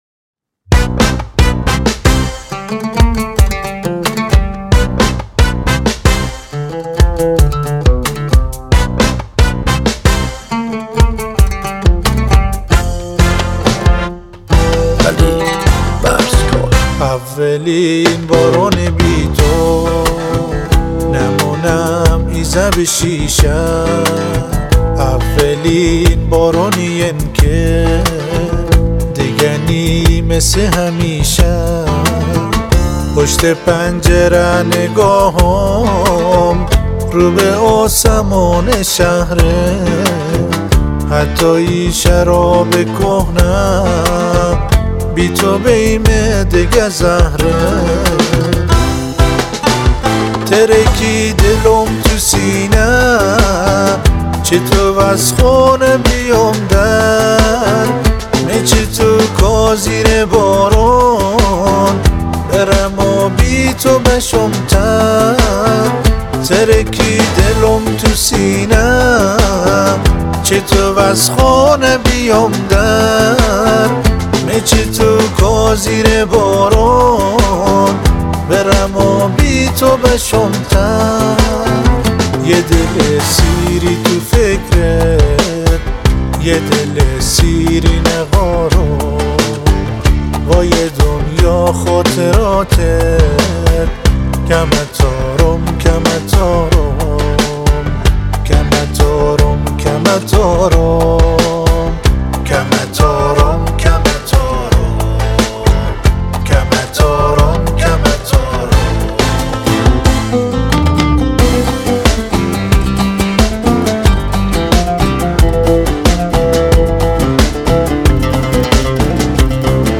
• تنظیم و گیتار باس
• عود